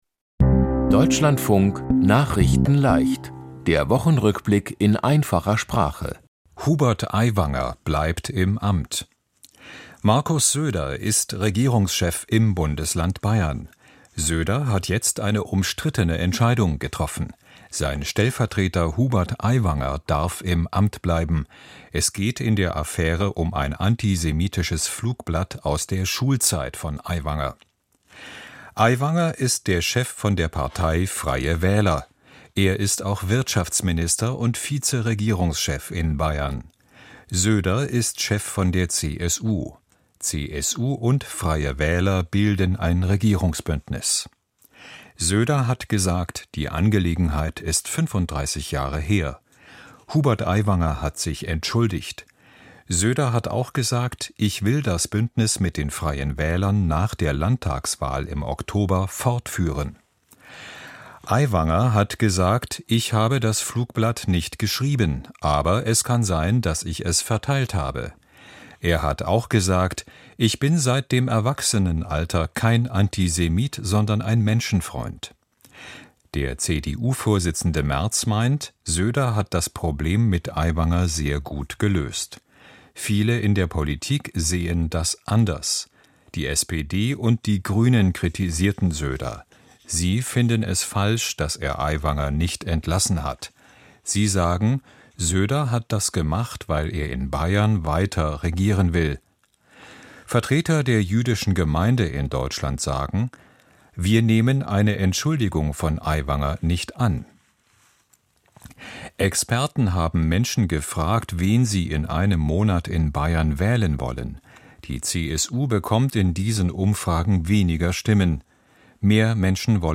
Der Wochen-Rückblick in Einfacher Sprache